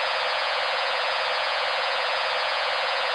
The sonification
assigning a sine
between 500 and
5000 Hz for 256